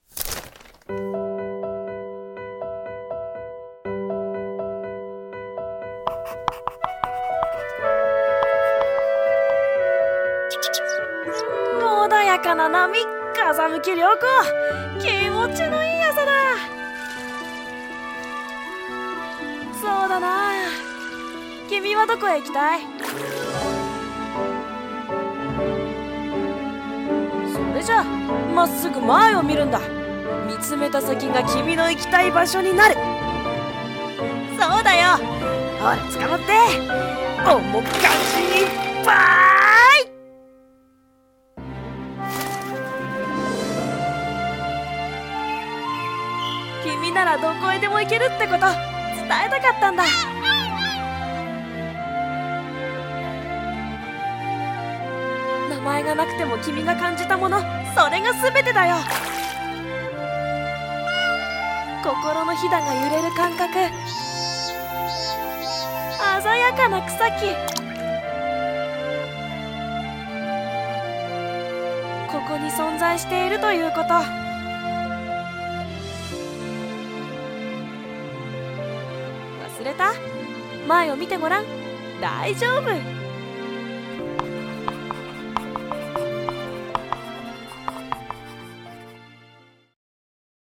声劇】名前のない航海記